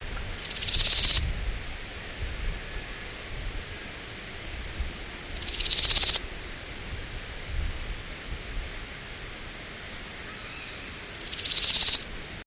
������������ ������� ����������� Chrysochraon dispar.